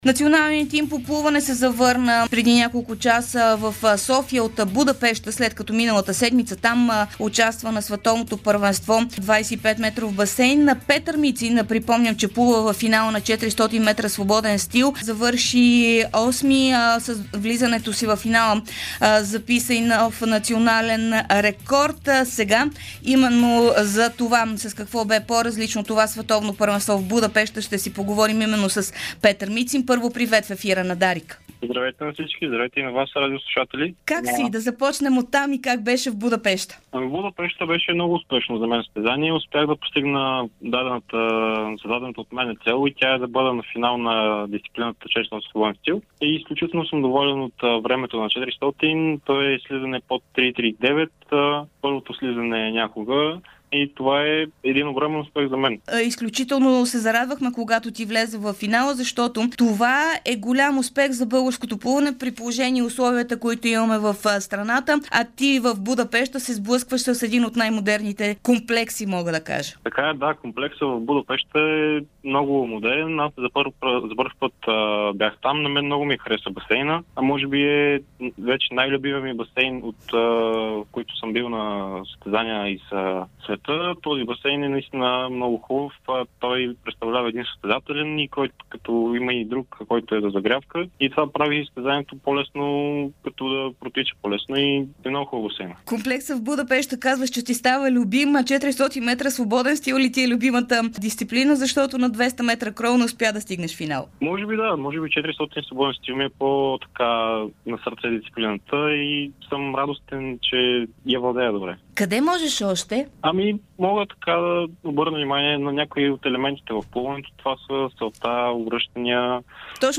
Българският плувец